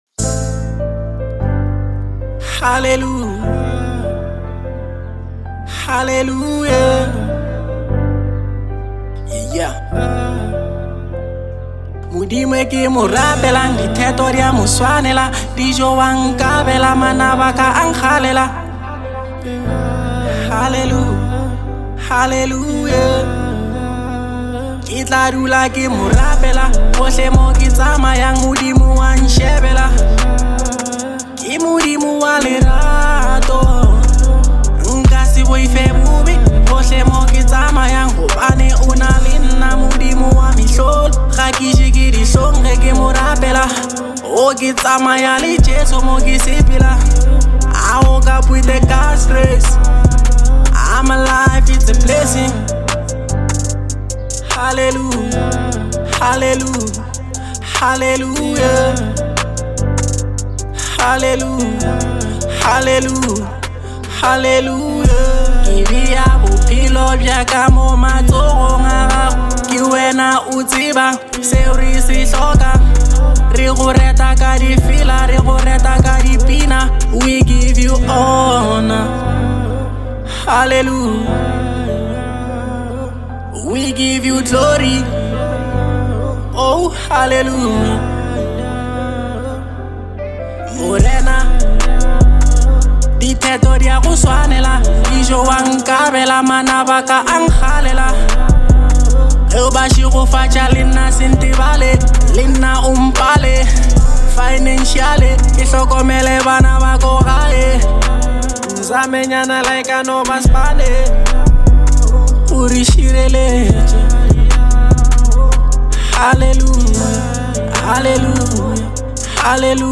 02:36 Genre : Hip Hop Size